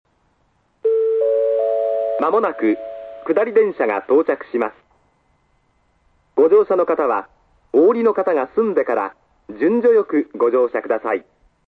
●スピーカー：ソノコラム・小
●音質：D
１番線 接近放送・下り（大牟田方面）　(57KB/11秒）
男性声のみの行先を言わない簡易放送ですが、「上り」「下り」の２パターンあります。